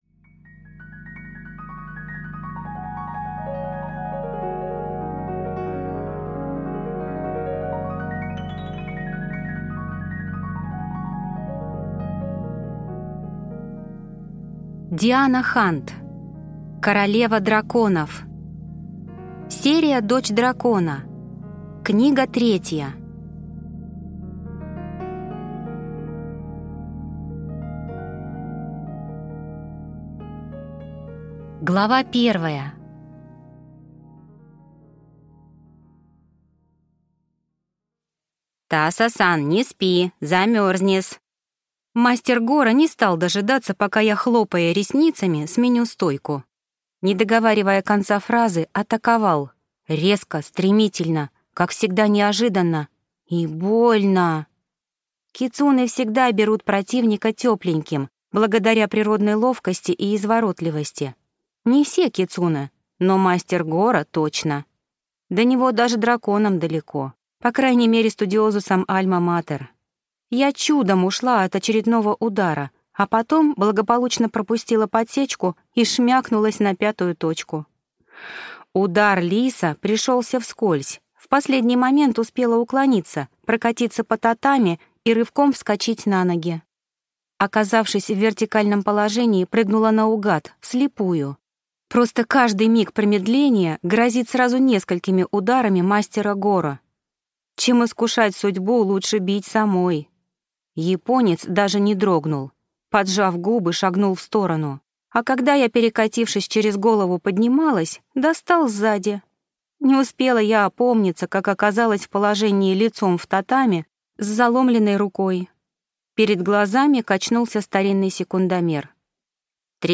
Аудиокнига Королева-дракон(ов) | Библиотека аудиокниг